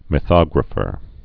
(mĭ-thŏgrə-fər)